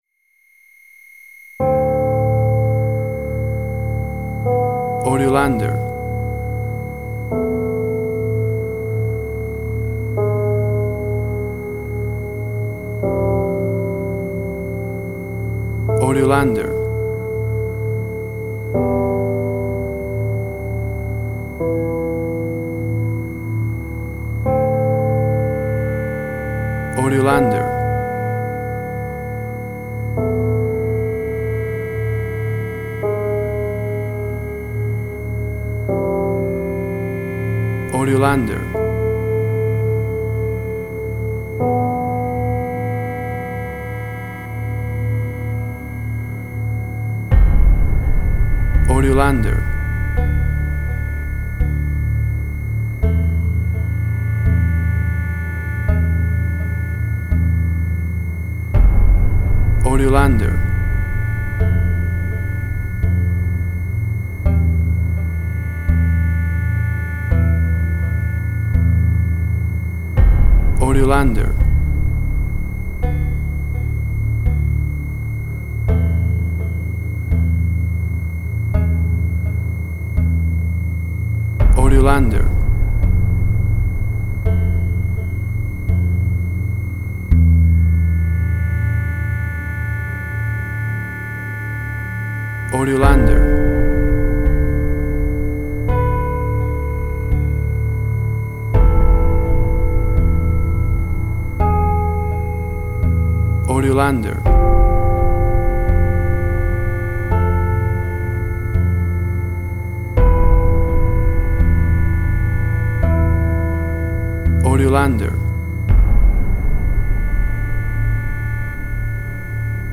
Suspense, Drama, Quirky, Emotional.
Tempo (BPM): 84